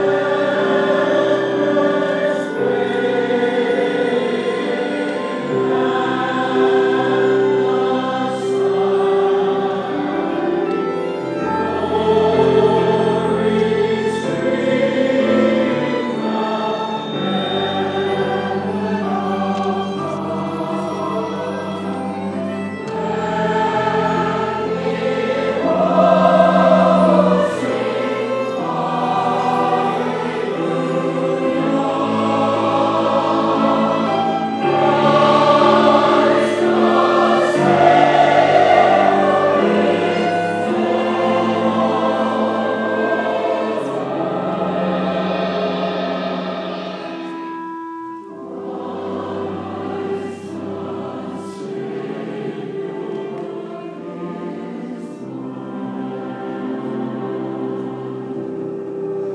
Segment from Christmas concert rehearsal at 1st Christian Church, Las Vegas, NV Sunday 7pm.